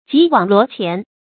吉網羅鉗 注音： ㄐㄧˊ ㄨㄤˇ ㄌㄨㄛˊ ㄑㄧㄢˊ 讀音讀法： 意思解釋： 唐天寶初，李林甫為相，任酷吏吉溫、羅希奭為御史。